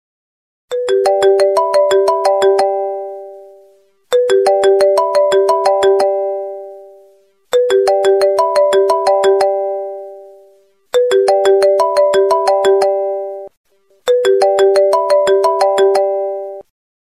تنزيل نغمة ايفون 11